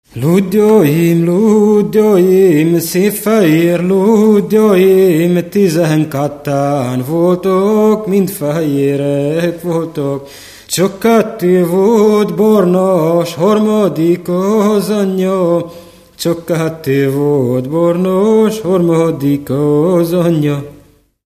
Moldva és Bukovina - Moldva - Klézse
ének
Stílus: 7. Régies kisambitusú dallamok
Kadencia: 4 (b3) 4 1